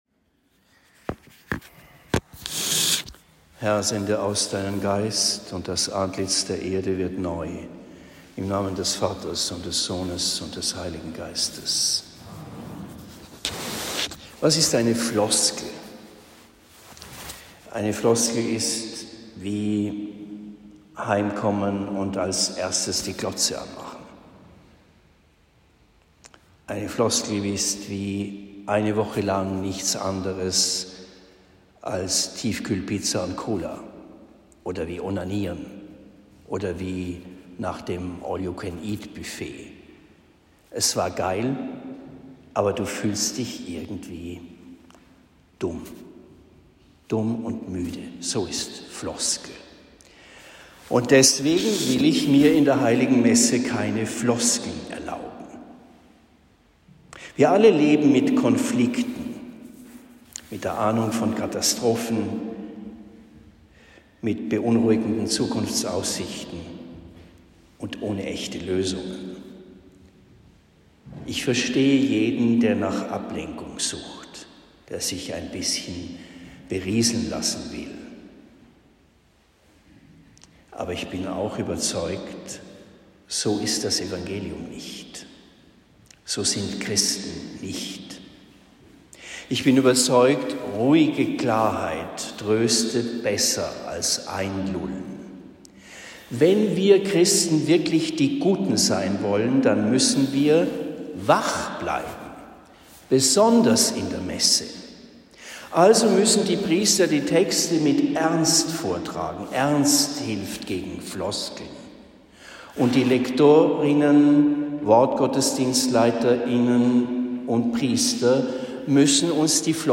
15. Jänner 2023 – Predigt in Homburg am Main